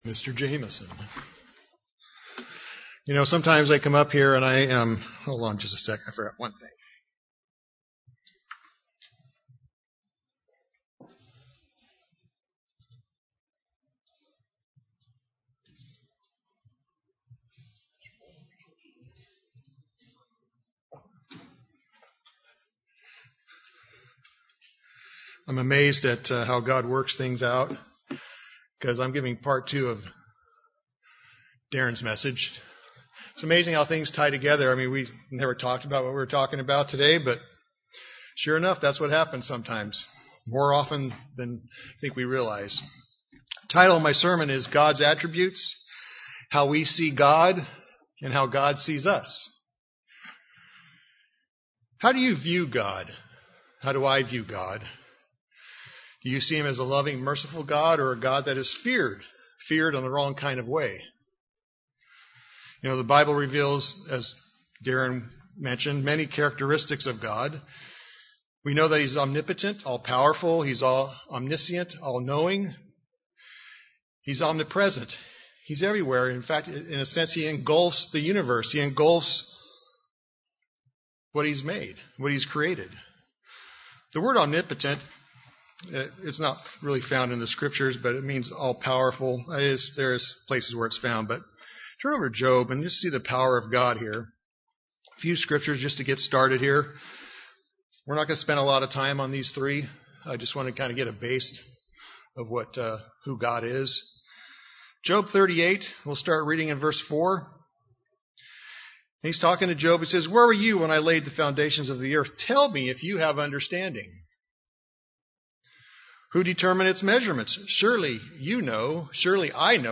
In this sermon we will look at three of them, then see how God views us.